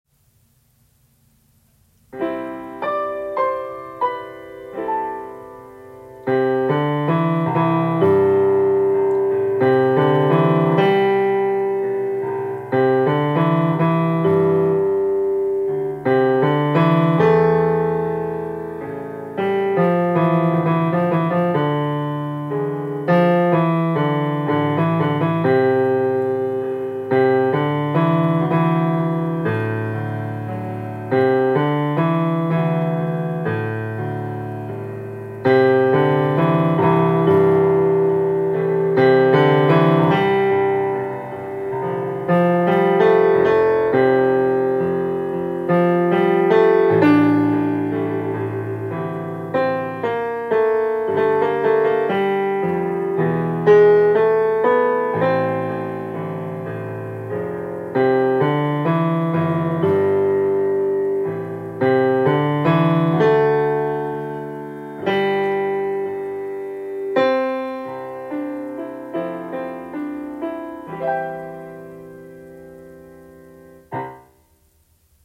BARITONE/BASS